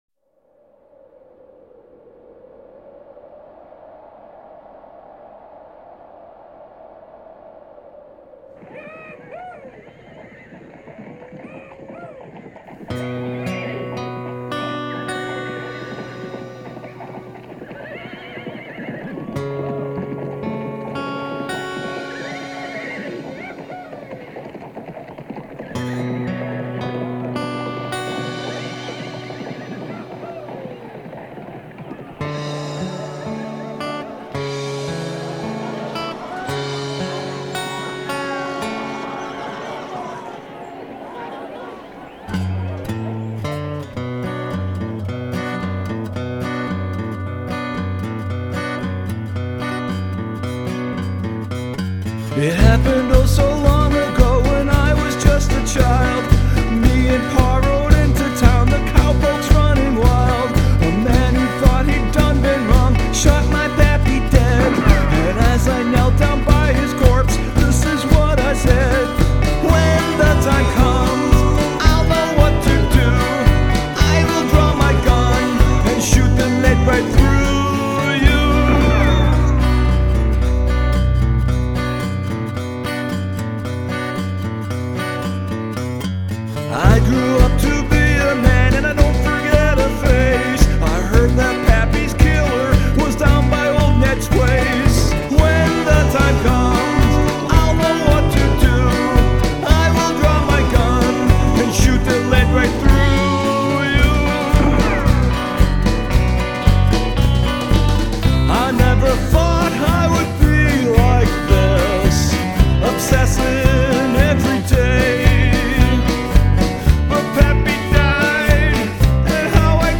Use of the sound of gunfire